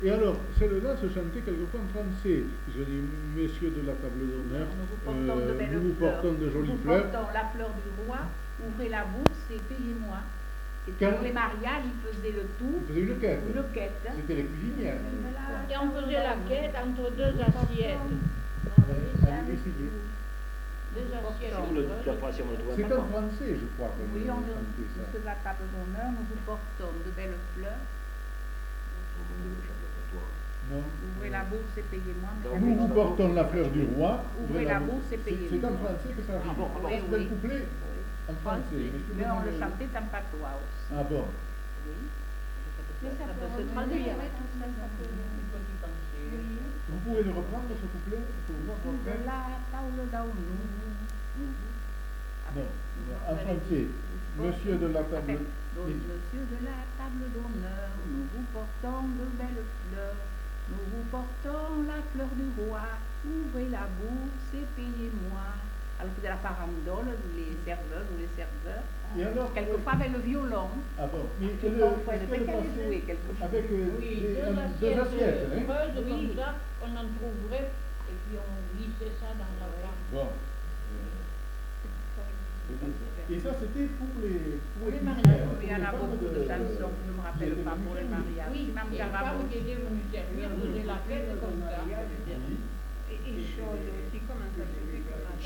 Aire culturelle : Bazadais
Lieu : Bazas
Genre : chant
Effectif : 1
Type de voix : voix de femme
Production du son : chanté ; récité